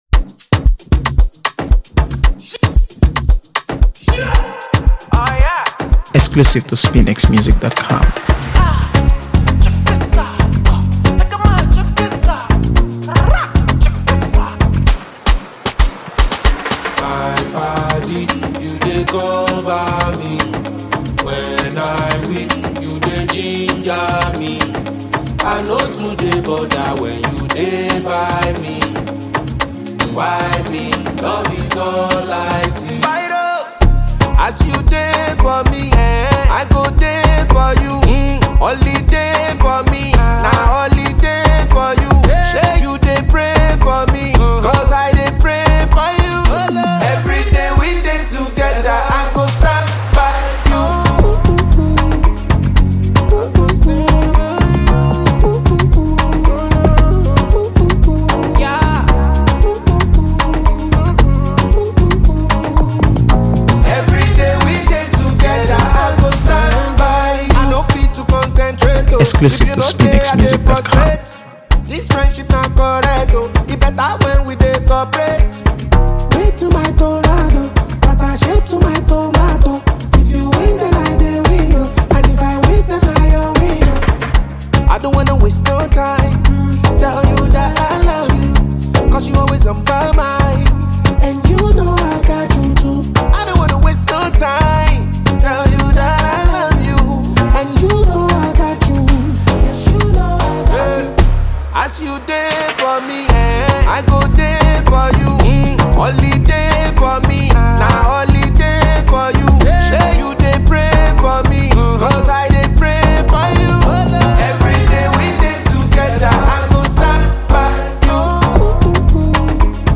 AfroBeats | AfroBeats songs
Soulful
smooth melodies and emotionally charged lyrics